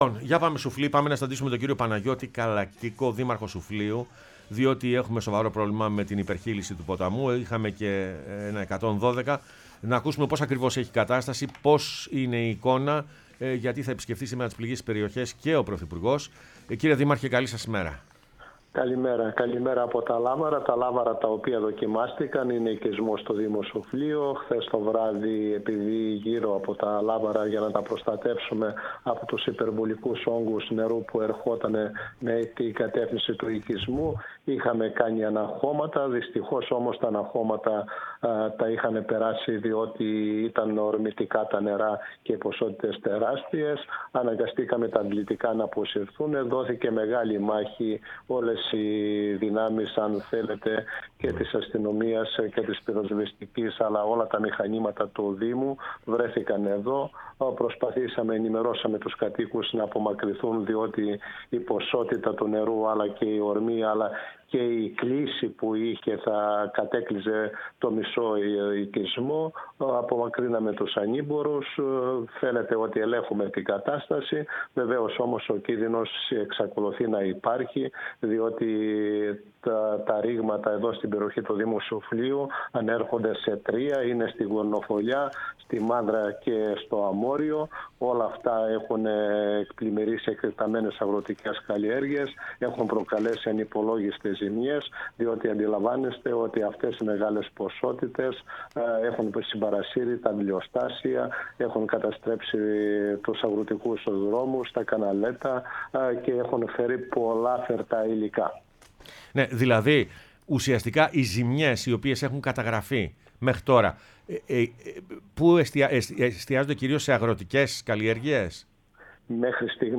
Παναγιώτης Καλακίκος, Δήμαρχος Σουφλίου μίλησε στην εκπομπή «Πρωινές Διαδρομές»
dimarxos-soufliou-1.mp3